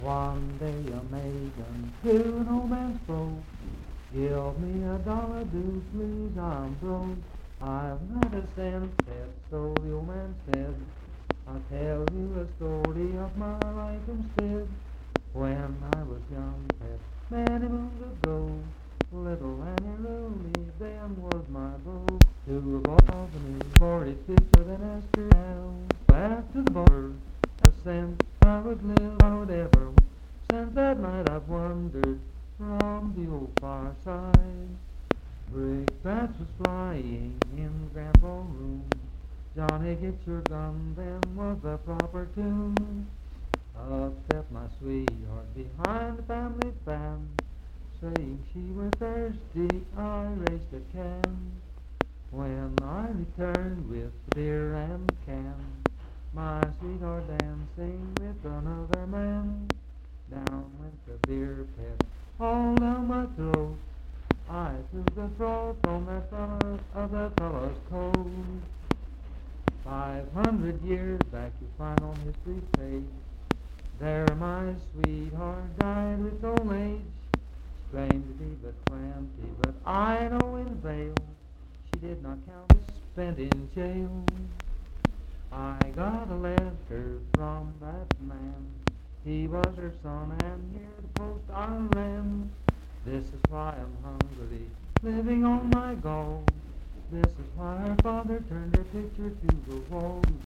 Voice (sung)
Pocahontas County (W. Va.), Marlinton (W. Va.)